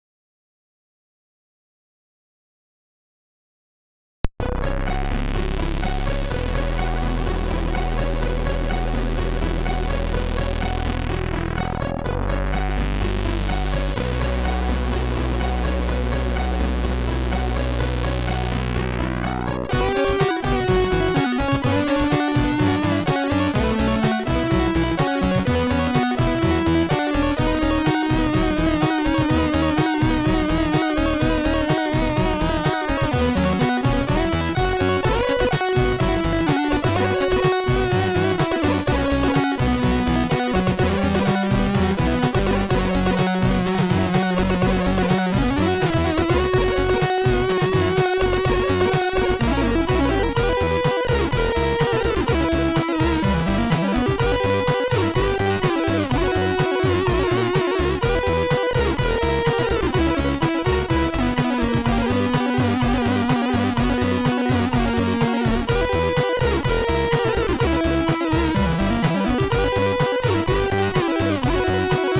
SID Version: 8580 (PAL)